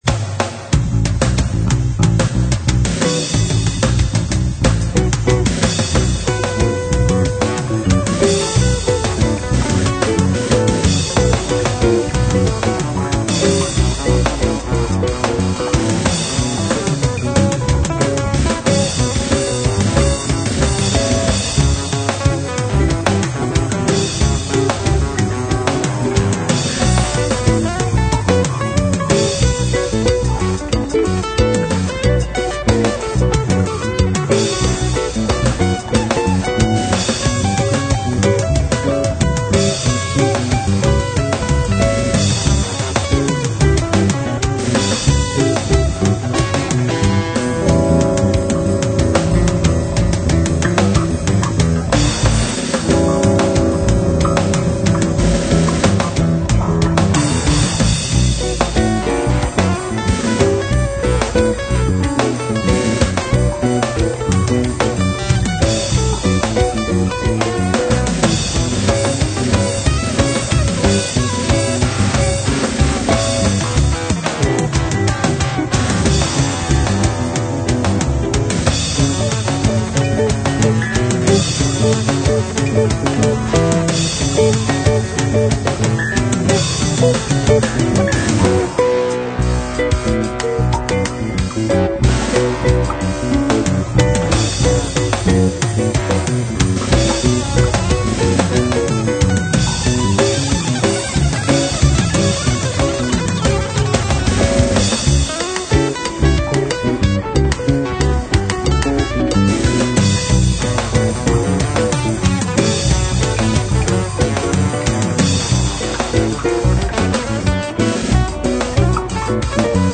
Supercharged V8 in action!